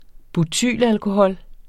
Udtale [ buˈtyˀl- ]